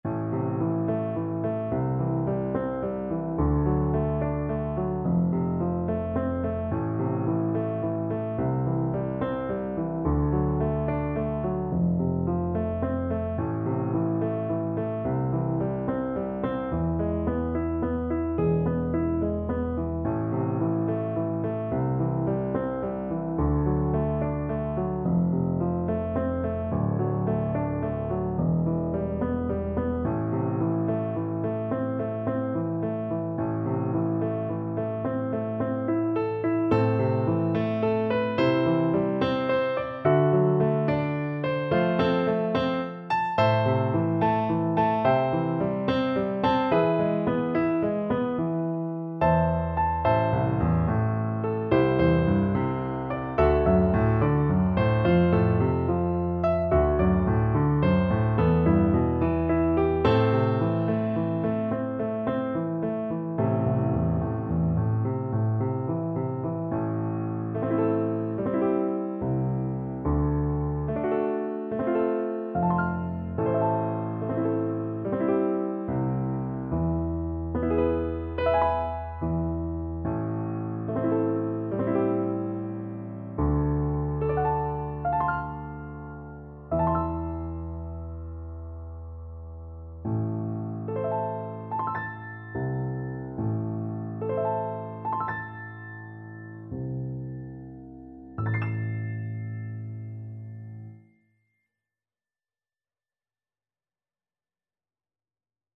House_of_the_rising_sun_HARM_kar1.mp3